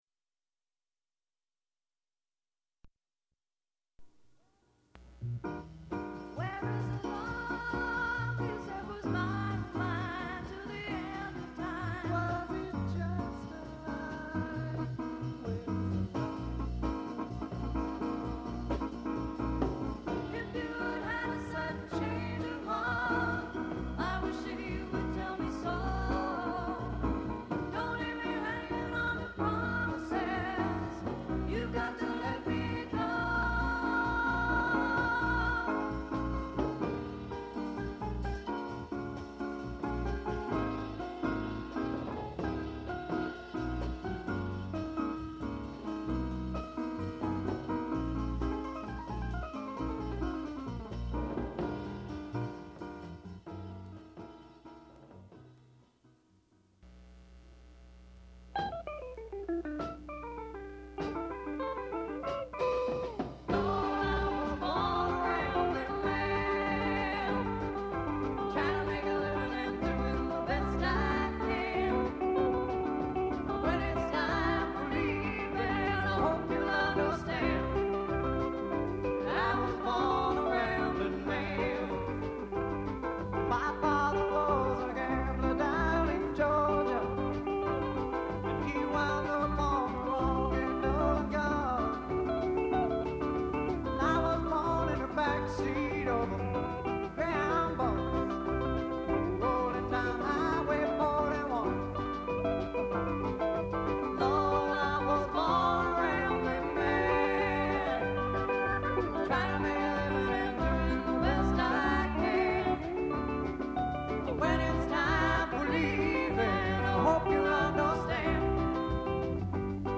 drums
guitar
percussion
keyboards